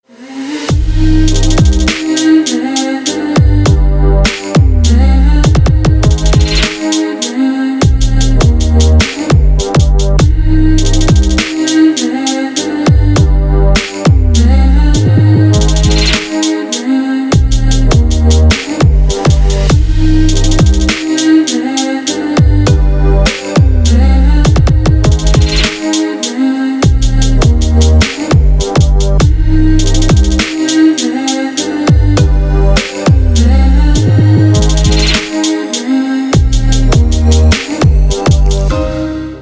теги: красивый рингтон